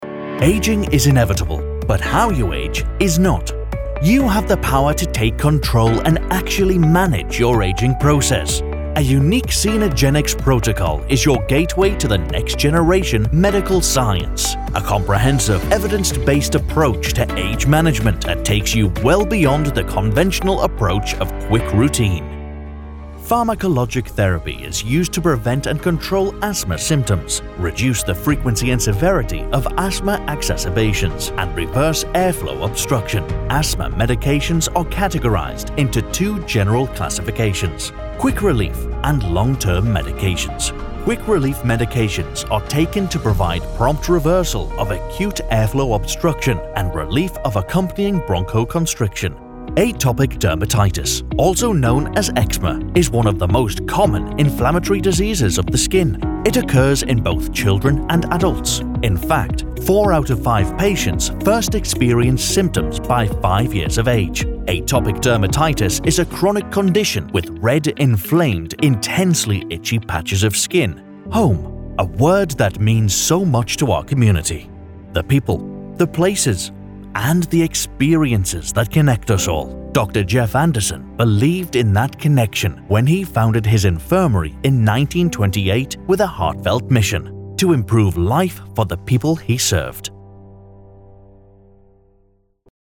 Male
British English (Native)
Approachable, Assured, Cheeky, Confident, Conversational, Corporate, Energetic, Reassuring, Sarcastic, Smooth, Warm
Geordie, RP, British, American
Microphone: Neumann TLM103 / Senheisser MKH-416 / Rode NT2